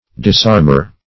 Disarmer \Dis*arm"er\, n. One who disarms.